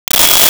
Sci Fi Beep 10
Sci Fi Beep 10.wav